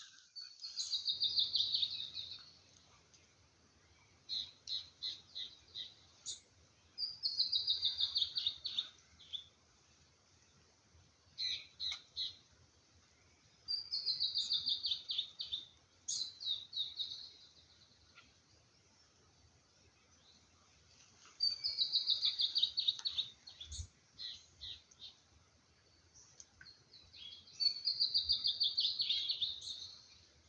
Bertoni´s Antbird (Drymophila rubricollis)
Location or protected area: Rancho Queimado
Condition: Wild
Certainty: Observed, Recorded vocal
TILUCHI-COLORADO.mp3